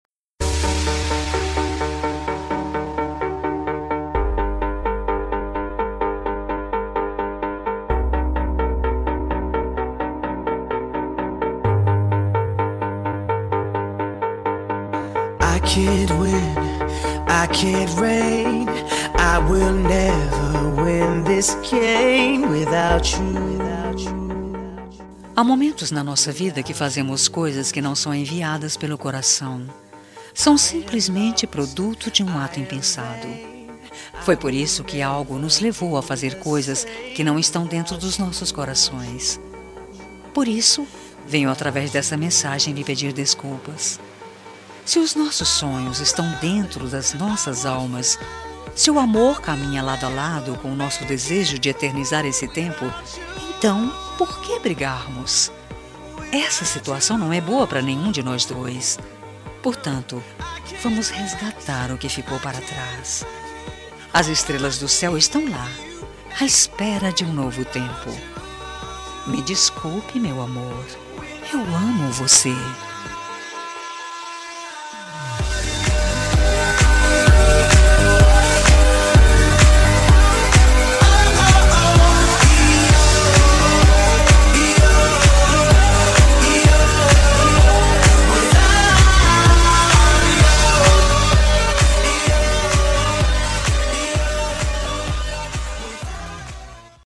Telemensagem de Desculpas – Voz Feminina – Cód: 202036